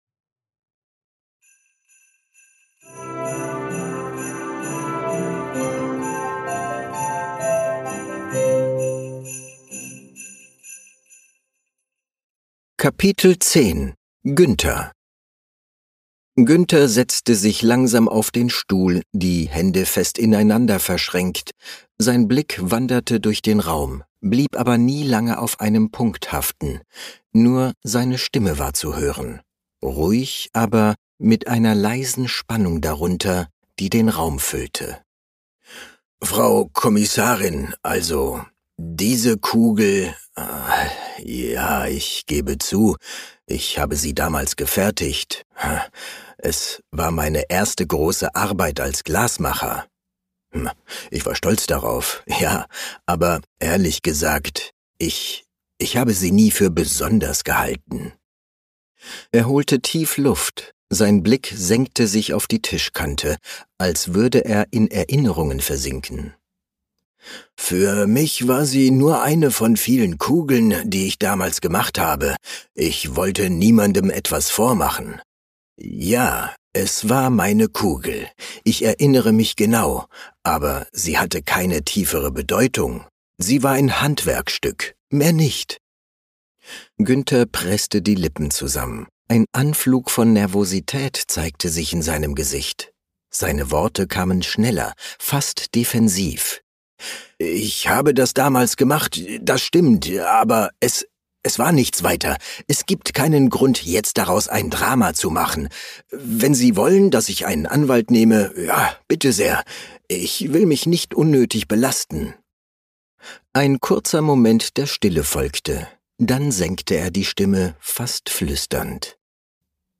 Kriminalgeschichte. Lass dich von acht verzaubernden Stimmen in die